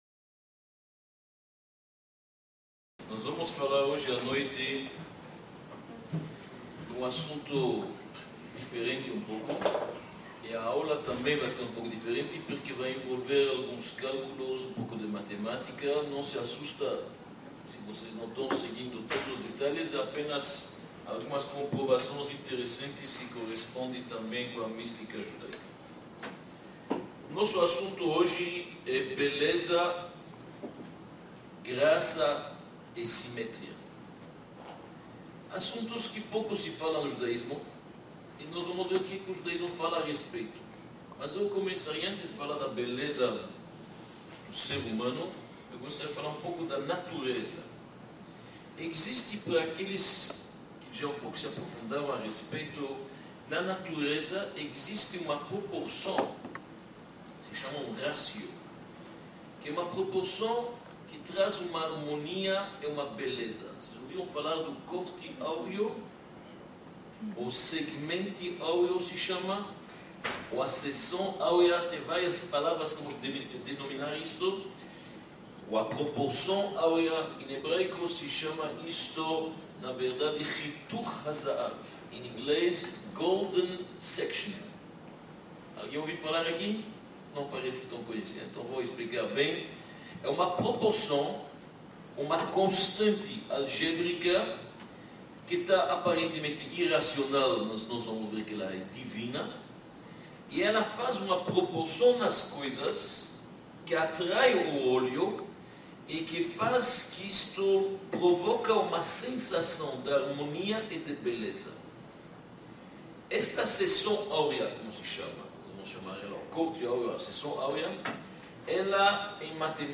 Palestra-Beleza-Graça-e-Simetria.mp3